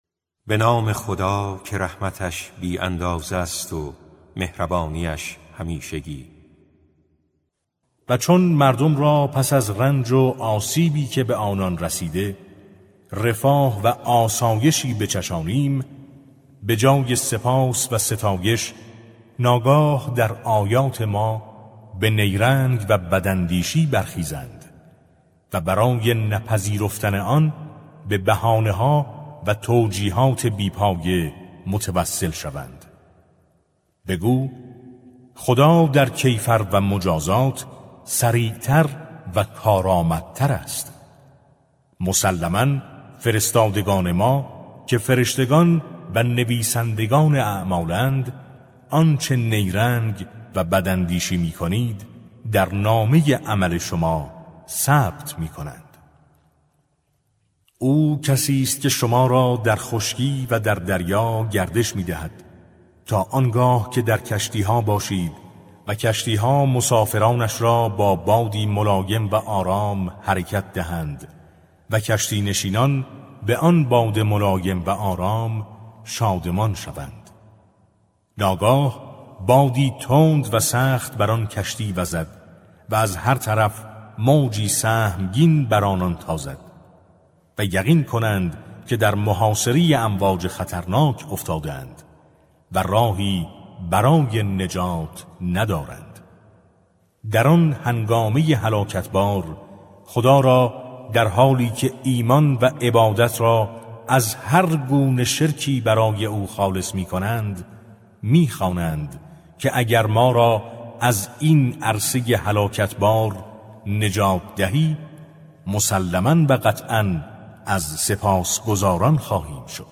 ترتیل صفحه ۲۱۱ سوره سوره یونس با قرائت استاد پرهیزگار(جزء یازدهم)
ترتیل سوره(یونس)